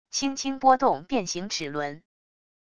轻轻拨动变形齿轮wav音频